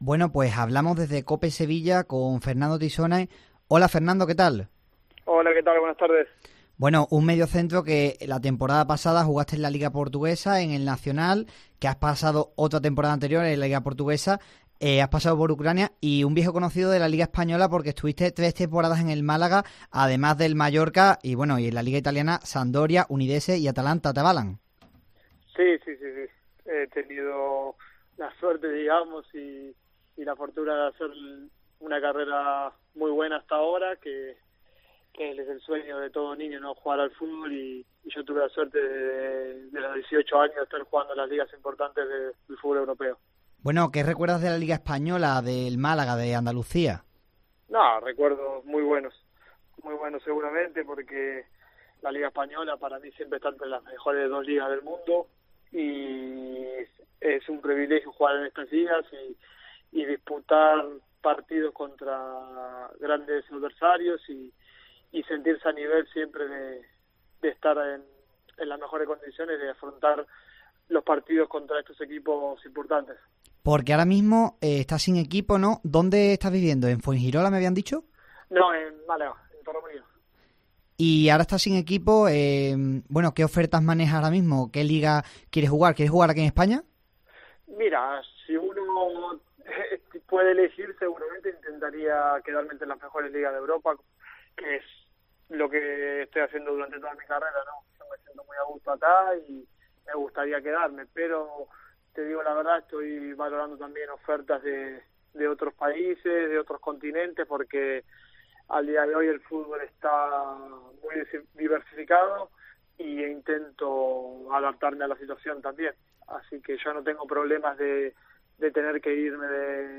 Fernando Tissone, que se encuentra sin equipo, fue entrevistado por Deportes Cope Sevilla, ya que está en la órbita del Betis